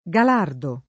[ g al # rdo ]